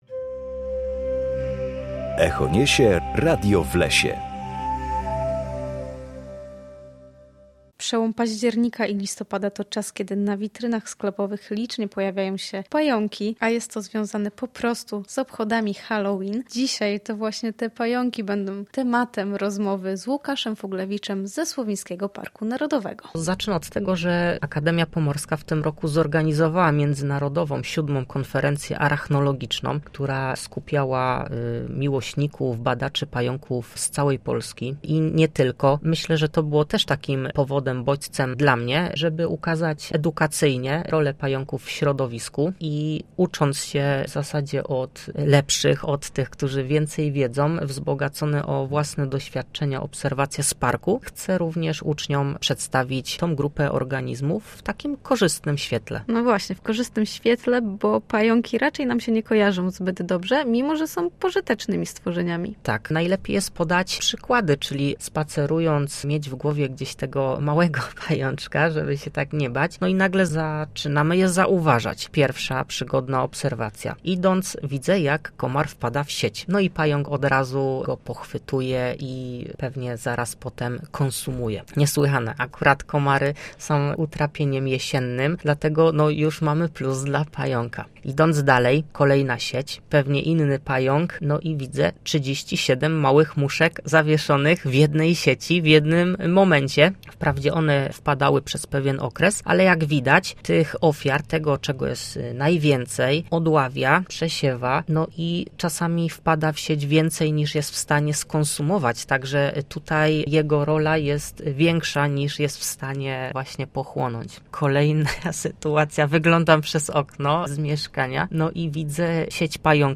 Zapraszamy do wysłuchania pierwszej części rozmowy: